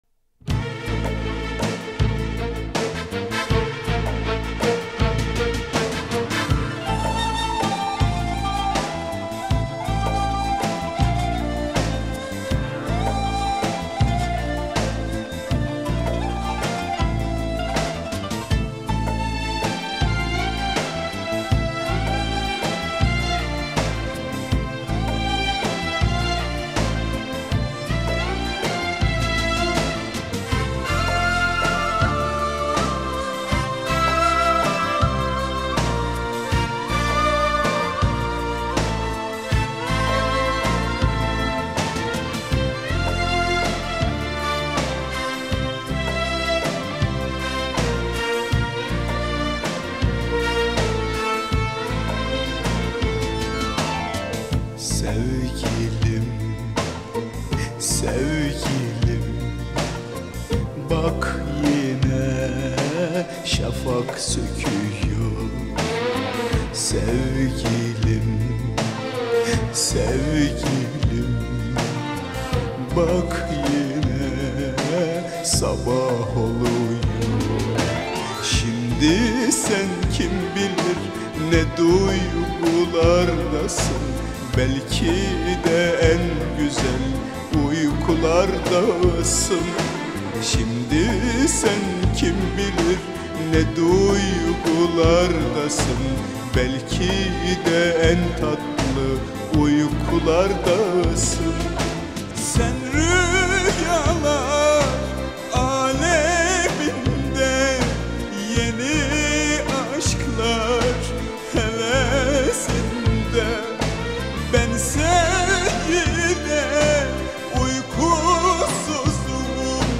آرابسک